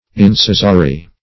Incisory \In*ci"so*ry\, a.
incisory.mp3